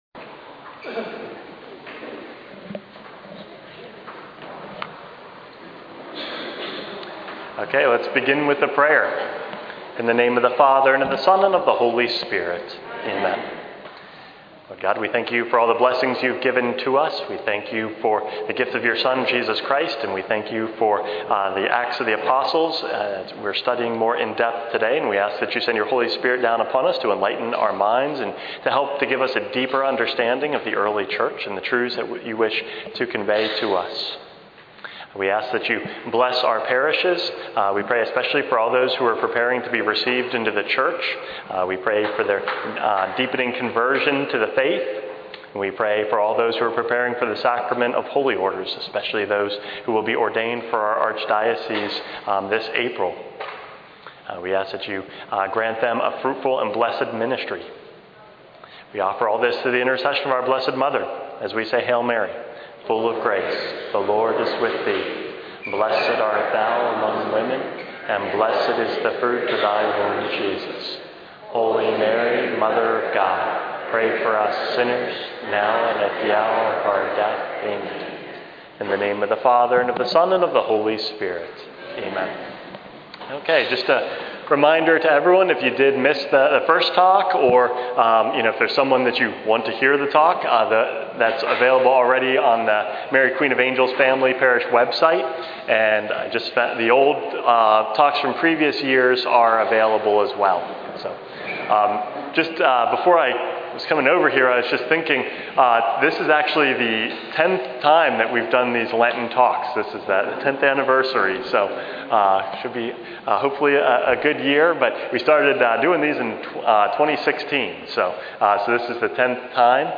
Lenten Talks 2025 - Part 2 of 2